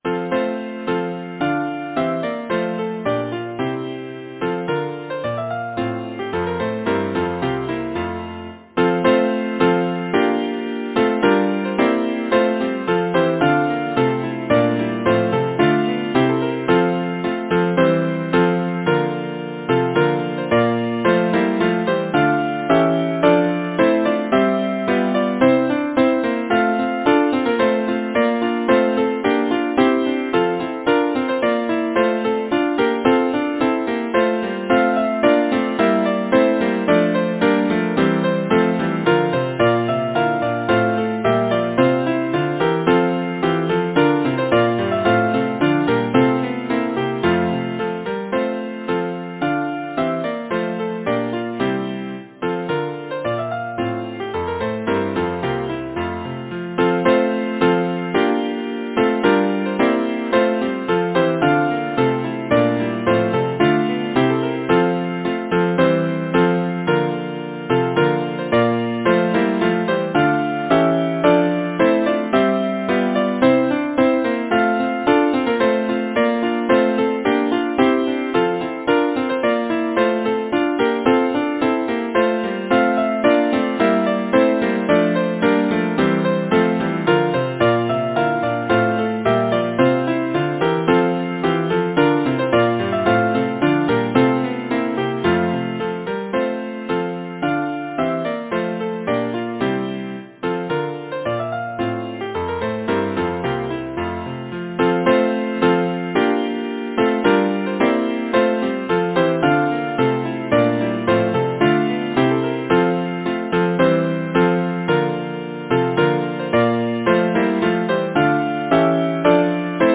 Title: Good Morrow to my Lady bright Composer: Clara Angela Macirone Lyricist: Mary Cowden Clarke Number of voices: 4vv Voicing: SATB Genre: Secular, Partsong
Language: English Instruments: Piano